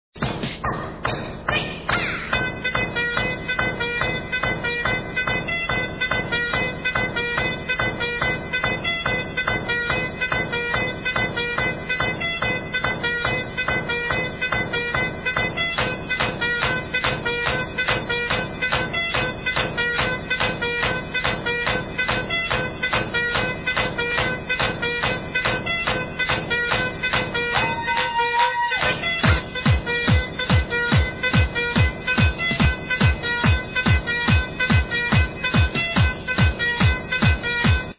Very OLD tune, dunno if it's really trance or not ...
Erm.. the first part sounds like something out of Mickey Mouse Club.. sorry can't help you here.
it just sounds gay and cheesy
Perhaps you should give us a better sample to try to identify as this one is just an intro to a beat i wouldn't even call trance.